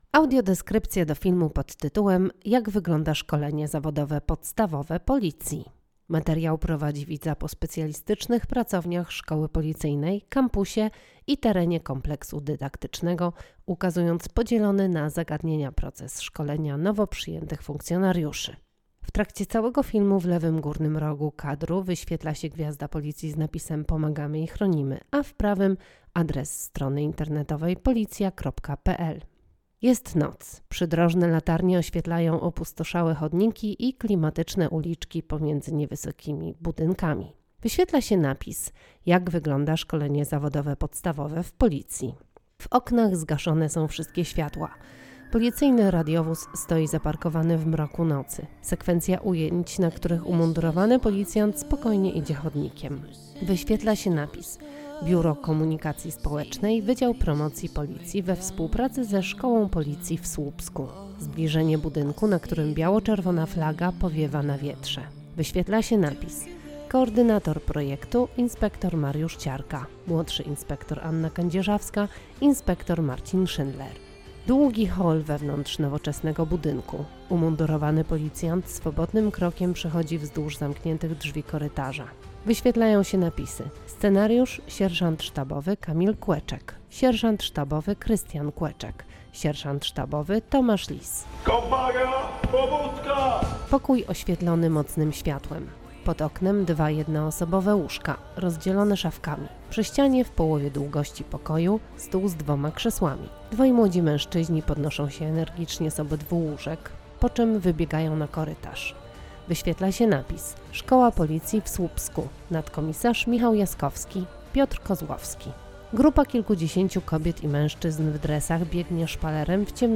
Nagranie audio Audiodeskrypcja do filmu: Jak wygląda szkolenie zawodowe podstawowe Policji